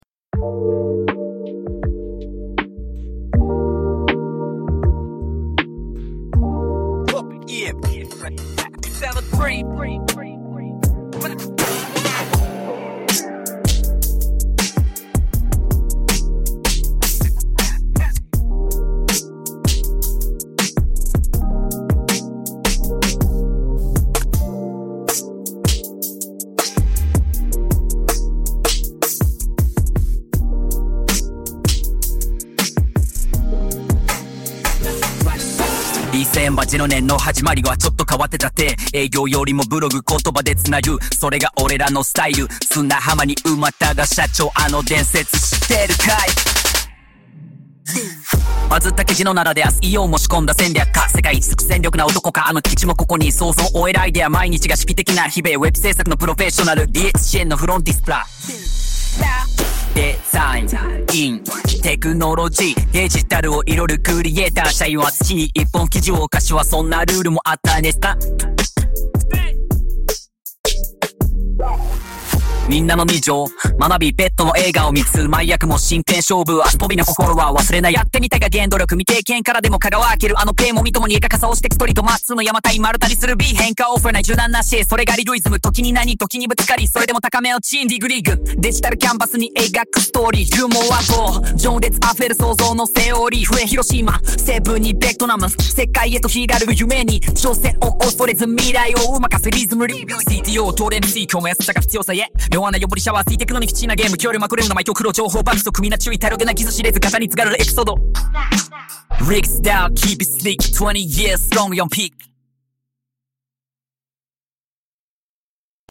Genre: Modern Hip Hop with fast male rap flow and stylish production
Tempo: 100 BPM, energetic but controlled groove
Instruments: deep and smooth bassline, crisp modern drum kit with tight snares and hi-hat rolls, subtle 808 elements, clean synth stabs, atmospheric pads for depth, occasional record scratch for texture
Mood: modern, cool, and celebratory; powerful enough to energize a 20th anniversary party, but not noisy or overwhelming; sleek and sophisticated with a festive edge
Intro: minimal beat and bass with atmospheric pad, short male vocal ad-lib, then beat drop
Outro: fade-out with bass and subtle scratches, leaving a cool aftertaste
AIボイスが読み上げると、自然なラップ調に仕上がりました。